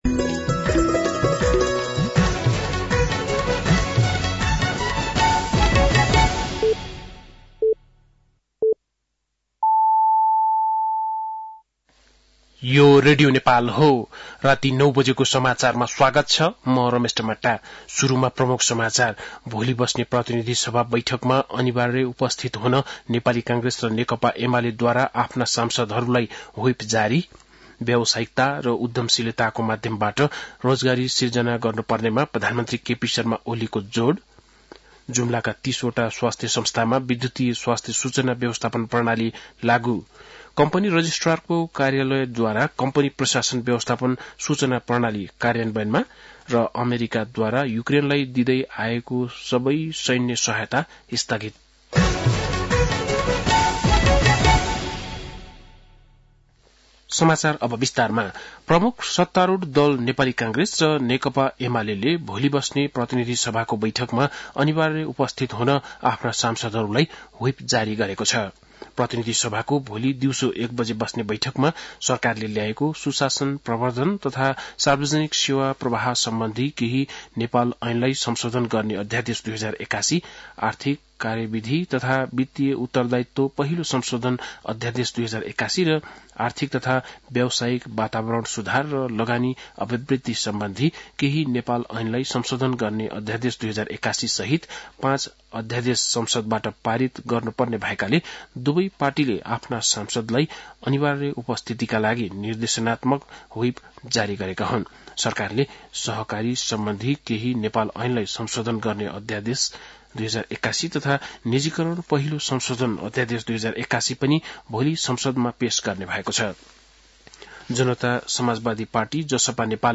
बेलुकी ९ बजेको नेपाली समाचार : २१ फागुन , २०८१